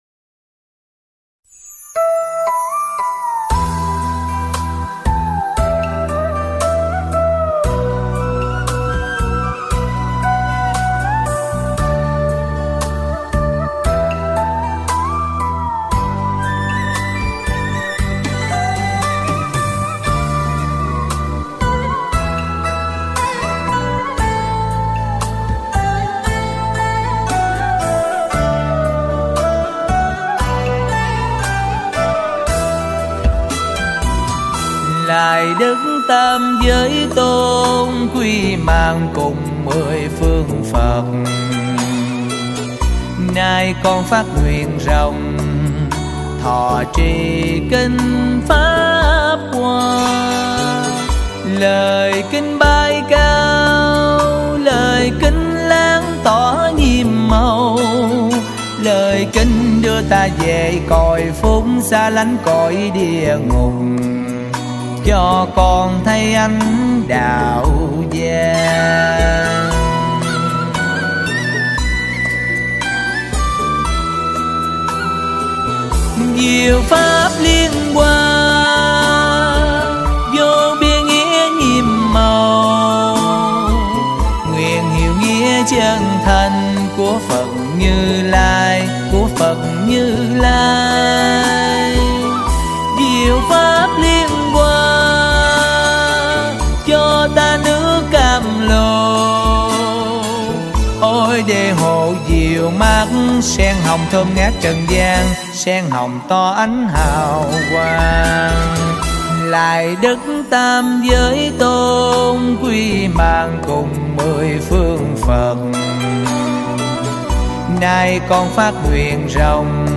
Âm Nhạc Phật Giáo Nghệ thuật Phật giáo